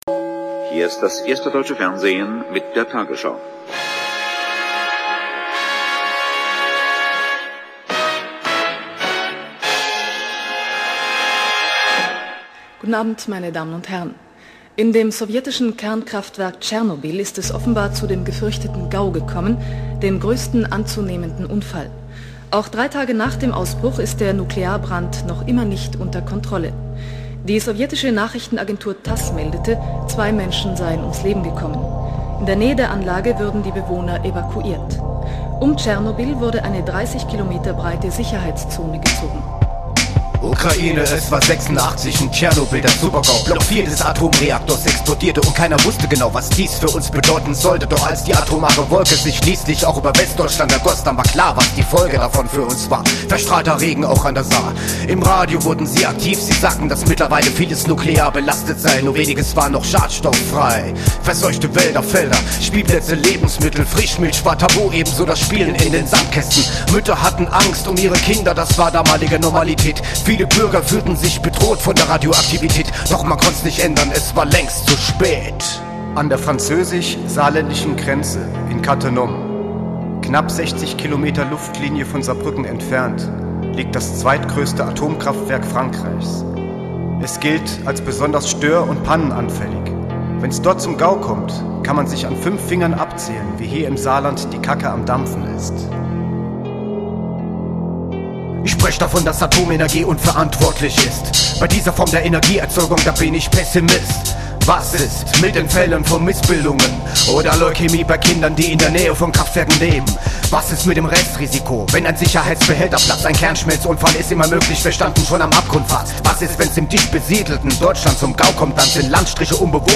Rap-Gruppe